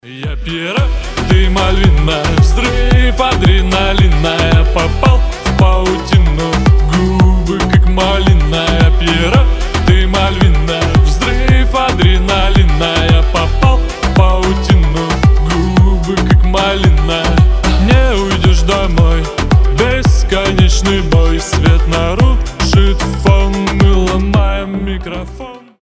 • Качество: 320, Stereo
ритмичные